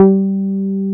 R MOOG G4P.wav